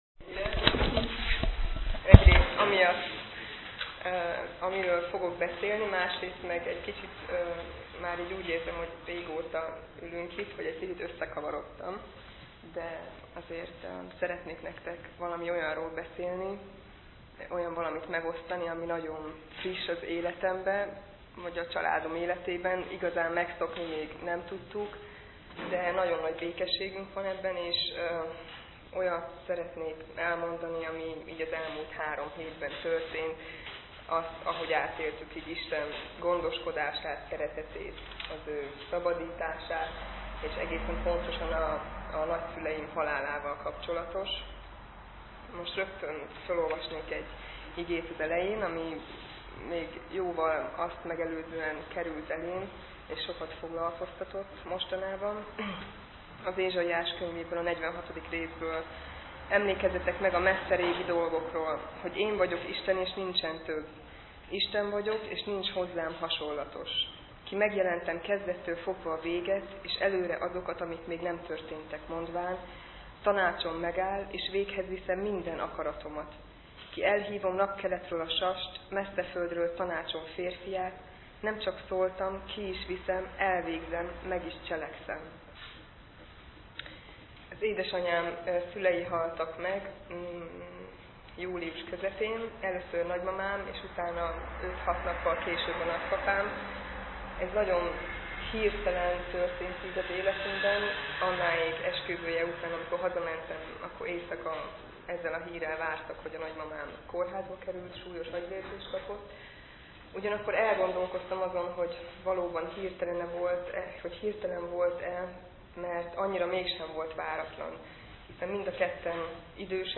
Bizonyságtétel - 2006. nyarán Neszmélyen